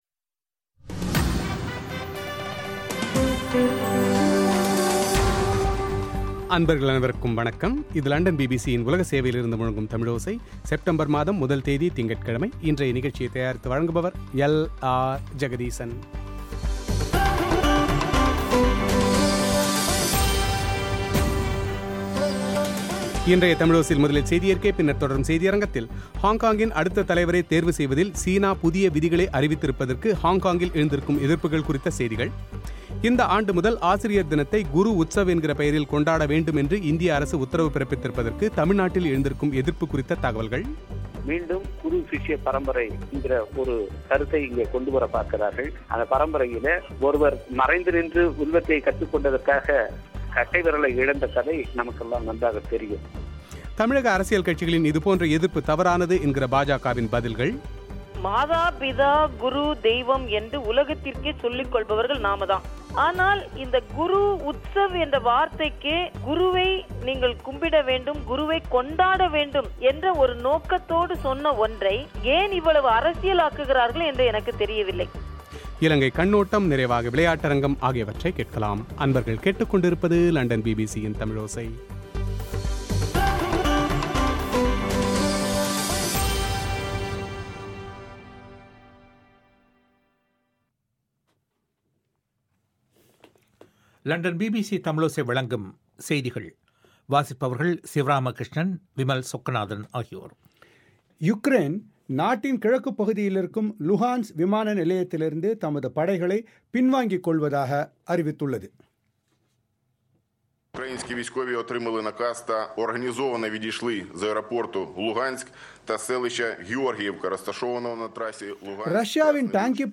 பிரத்யேக செவ்வி